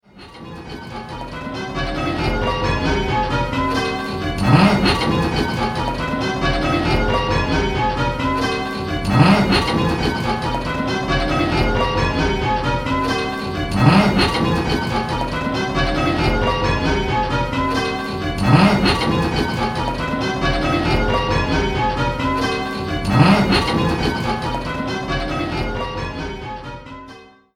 Where's the Treble: 10-Bell - Pebworth Bells